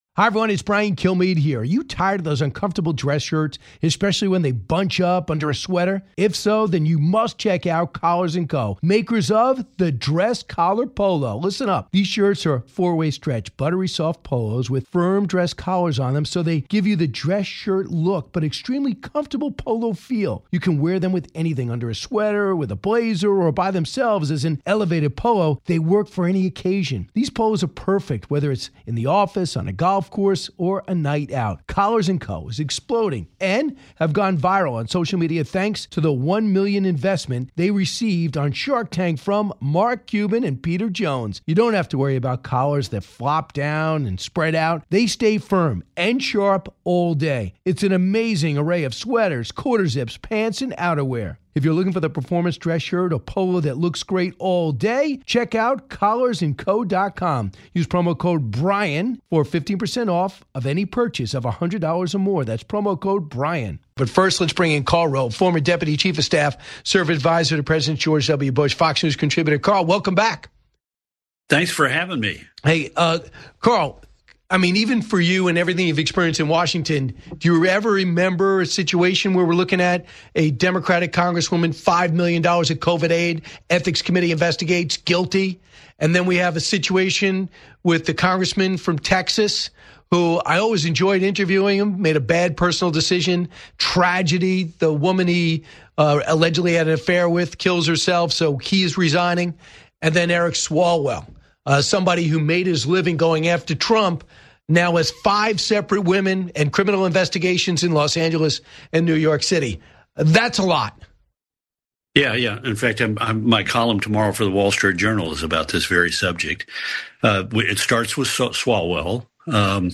Karl Rove joins the Brian Kilmeade Show to pull back the curtain on the scandals rocking Capitol Hill. Rove previews his latest Wall Street Journal column, contrasting the "performance art" of Rep. Eric Swalwell with the tragic downfall of Rep. Tony Gonzales. Plus, Rove sounds the alarm on "Democratic Socialists" and the exodus of businesses from New York to states like Texas and Florida.